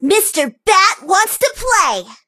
bibi_start_vo_06.ogg